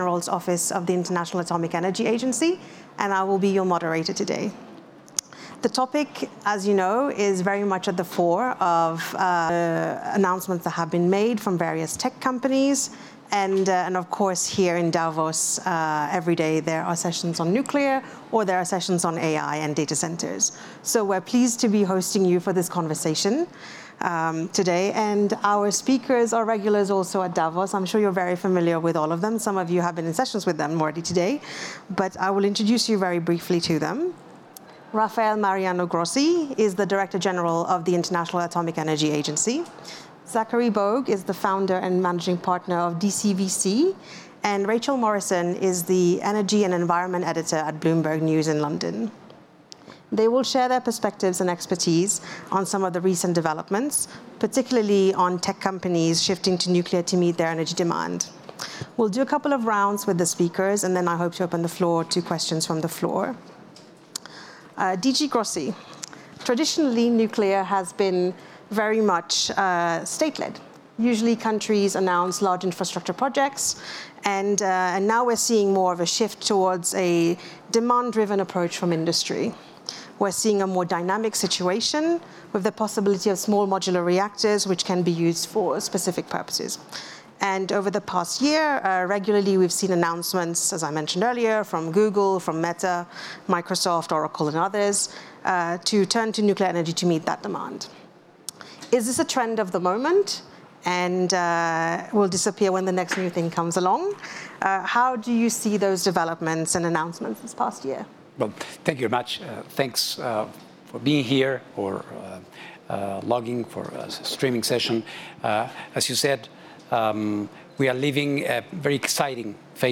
The IAEA held a session on nuclear's role in meeting energy demands for artificial intelligence (AI), with experts from Bloomberg and technology venture capitalists DCVC.
grossi-davos-2025.mp3